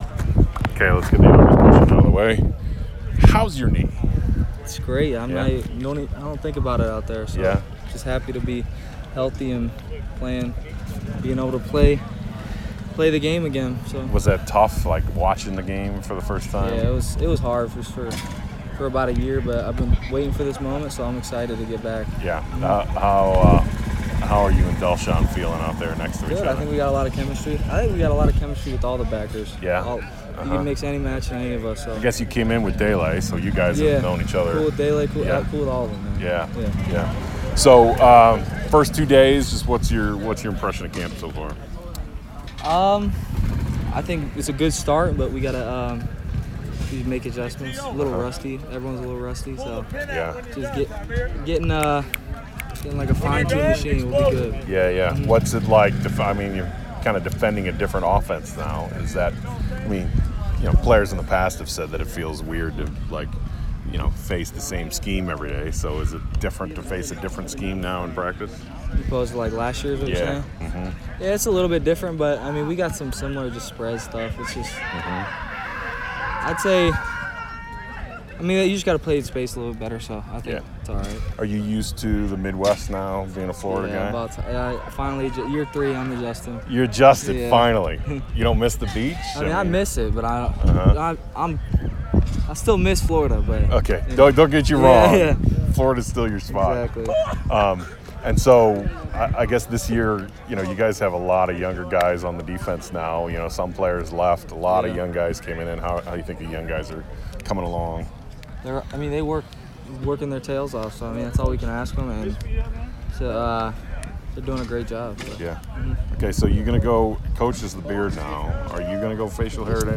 saturday post-practice interviews